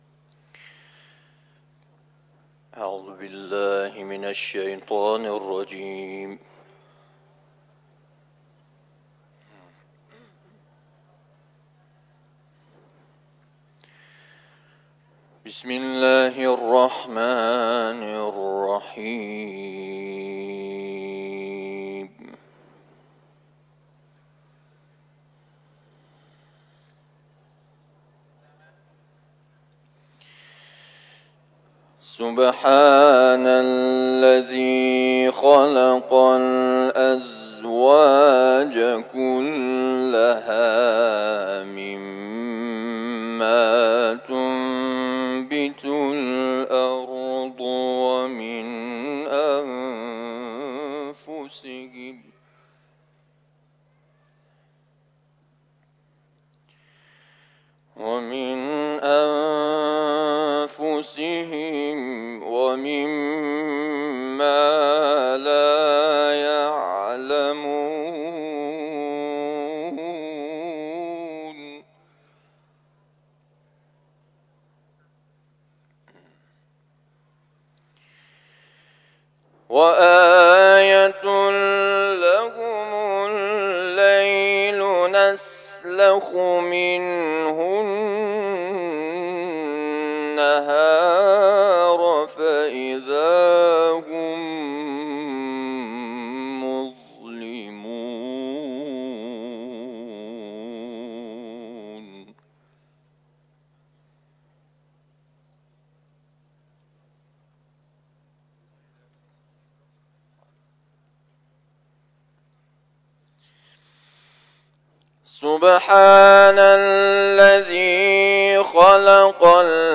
تلاوت
حرم مطهر رضوی ، سوره یاسین